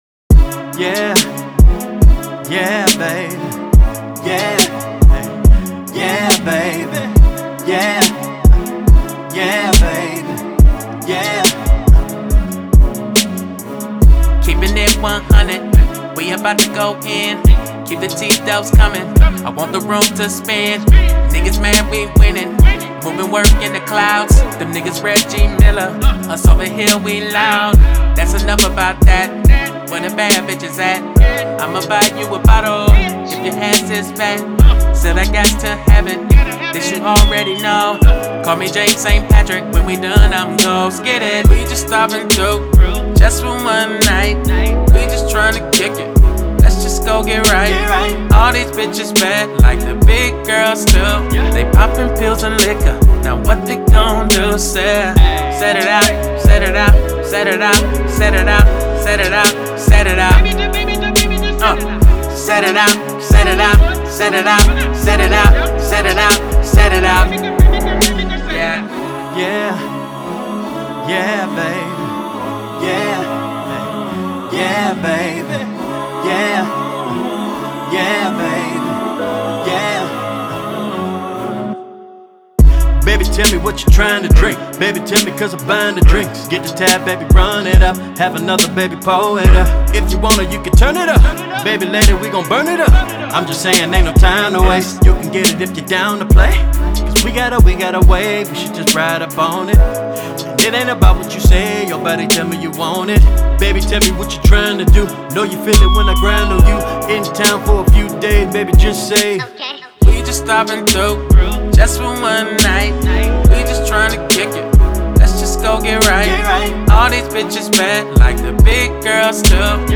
Hiphop
R&B duo